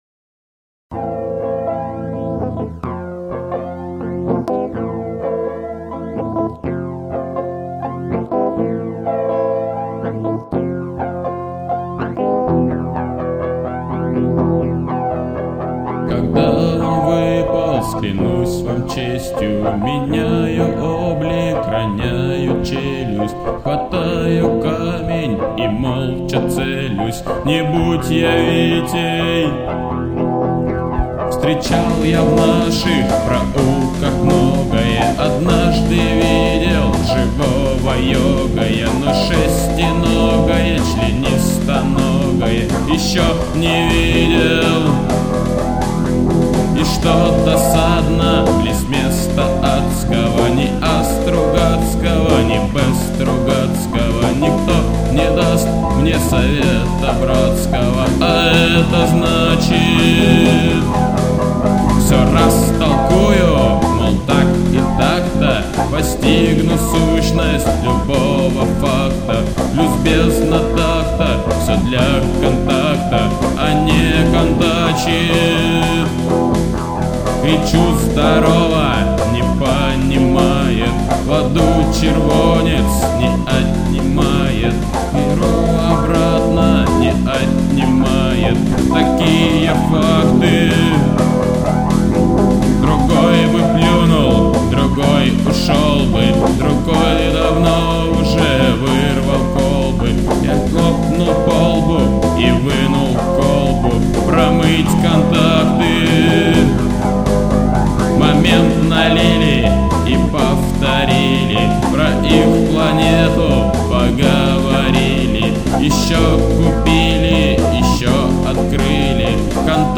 Демо